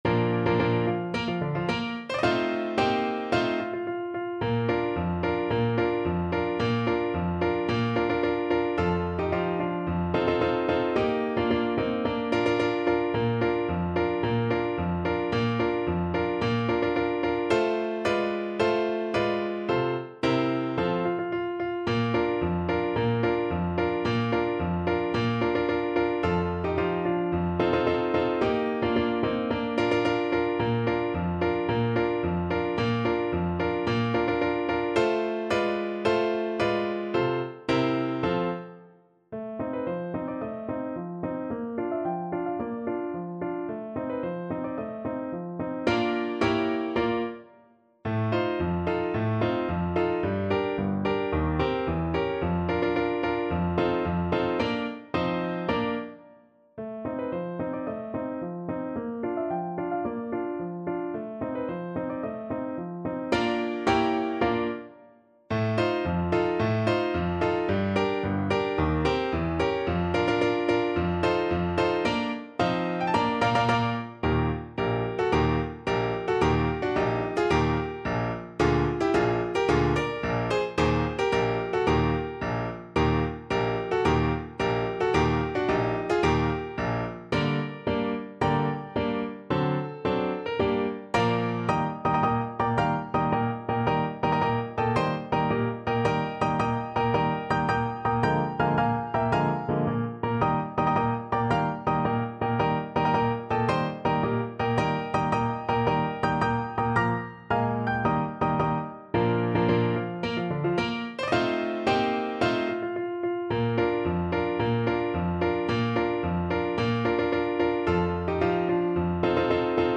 March =c.110
Classical (View more Classical French Horn Music)